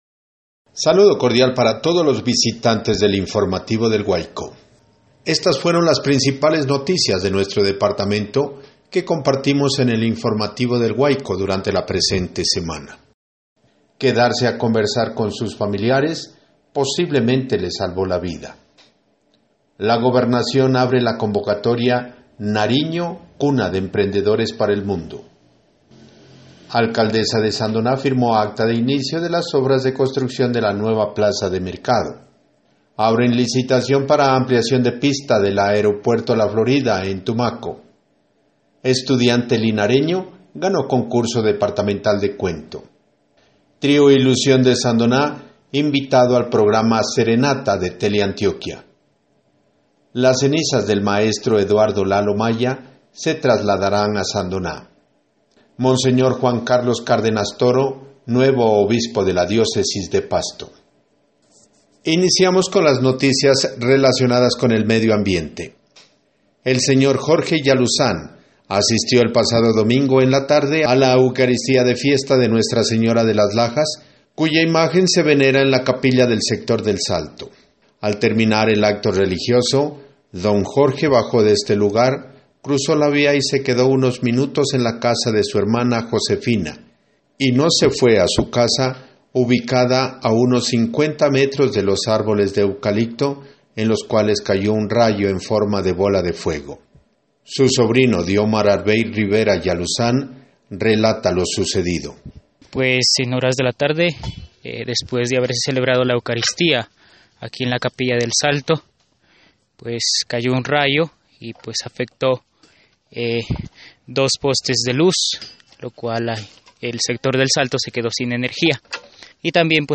Resumen semanal de noticias (audio 3)